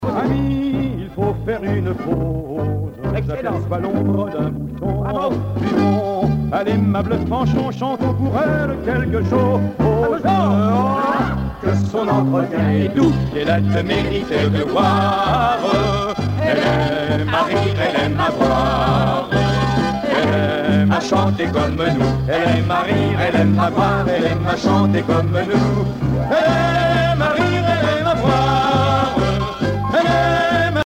danse : marche
circonstance : bachique
Pièce musicale éditée